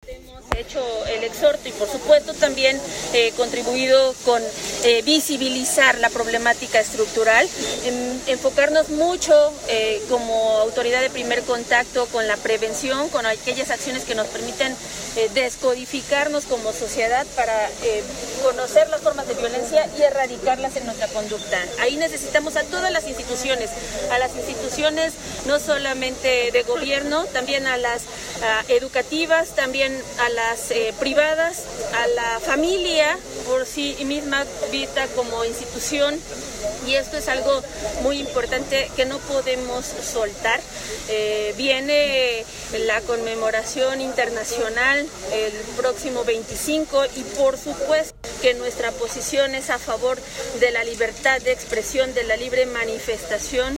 Rivera Vivanco, en entrevista, pidió a las y a los ciudadanos ser empáticos ante la problemática a que se está pasando por la pandemia que se vive en todo el mundo, incluida a la violencia feminicida como lo ha dado a conocer ONU mujeres; al recordar que desde el inicio de su administración se ha pronunciado en contra de este tipo de prácticas y de la impunidad.